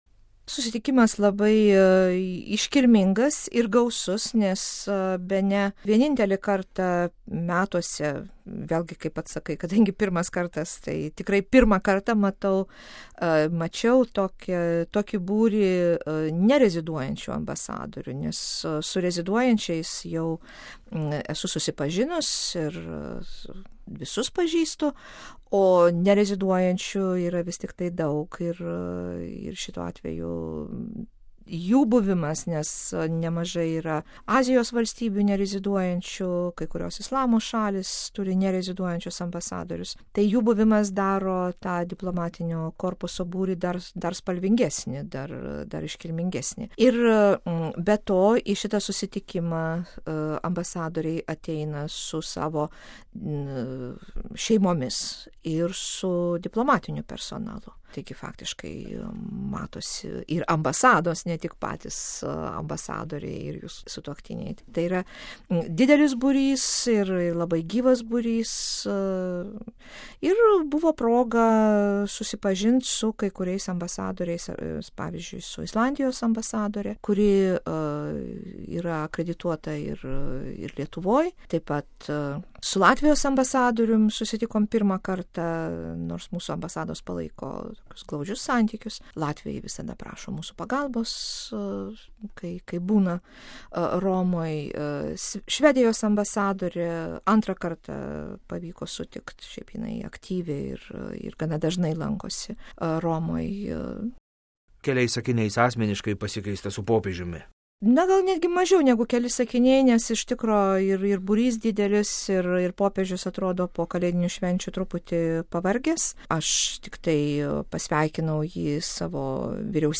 Iškart po Popiežiaus audiencijos įrašėme trumpą pokalbį, kuriame paprašėme papasakoti apie ir apie šį svarbiausią kasmetinį viso diplomatinio korpuso susitikimą, apie ambasadorės trumpą pokalbį su Popiežiumi ir, bendrai, apie Šventojo Sosto ir Katalikų Bažnyčios įtaką vadinamai „realiajai“ politikai ir diplomatijai, kurią šiuo metu vykdo valstybės.
Ambasadorė Irena Vaišvilaitė: RealAudio